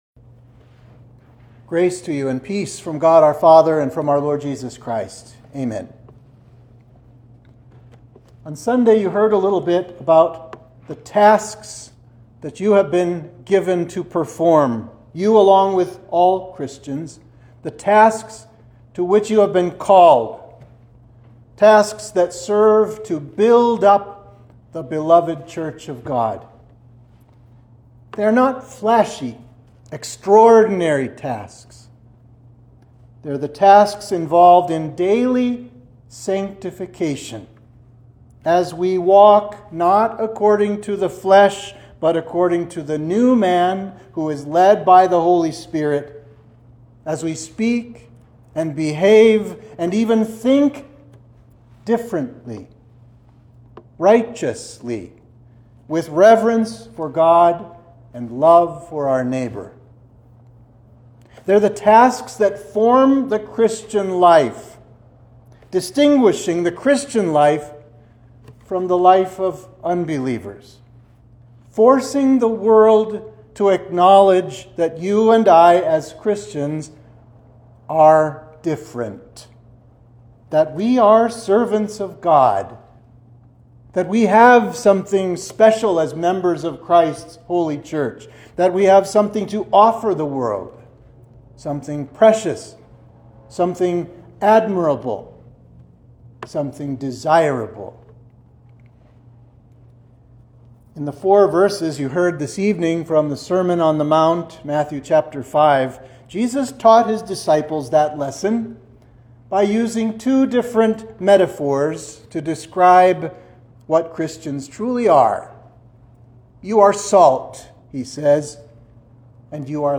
Sermon for Midweek of Trinity 5
(Only audio of the sermon is available for this service.)